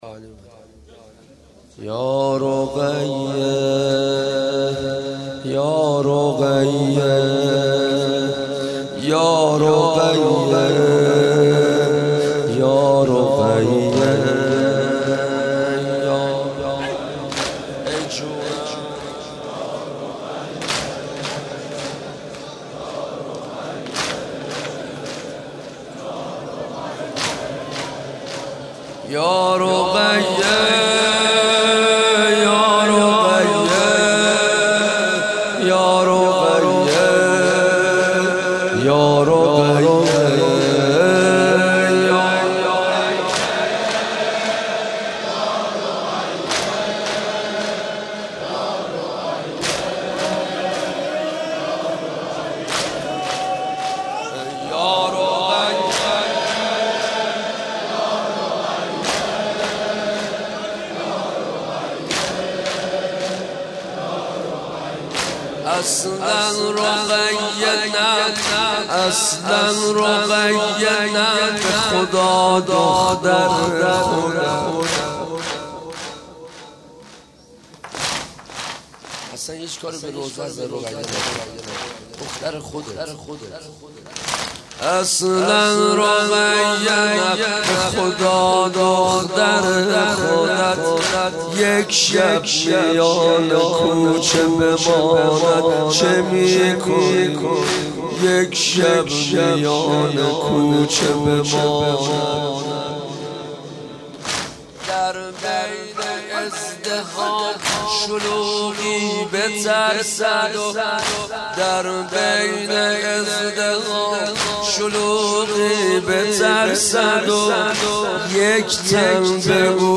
مناسبت : دهه دوم محرم